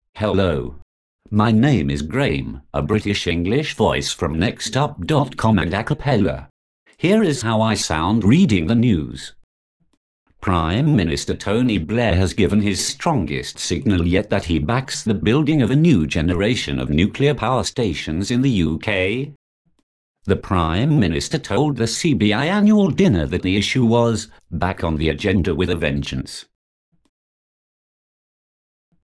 Acapela High Quality Text To Speech Voices; distribu� sur le site de Nextup Technology; homme; anglais am�ricain